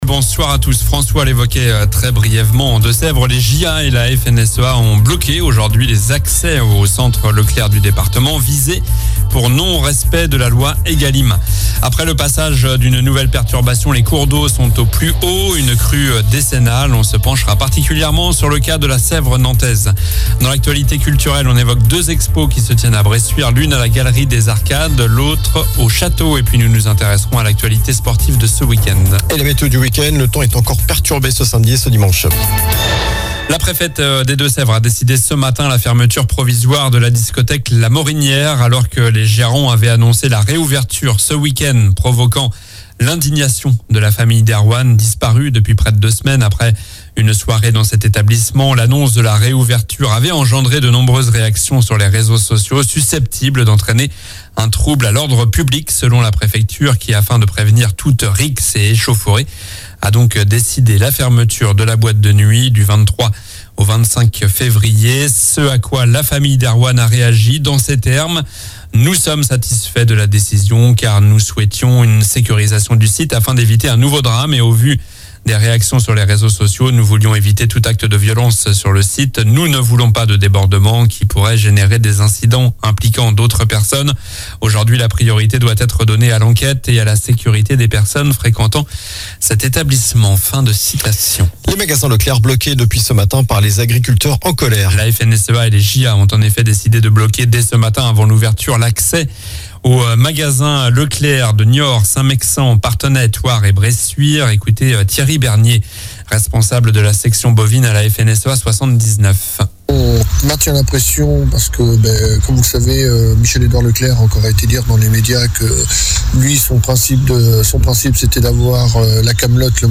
Journal du vendredi 23 février (soir)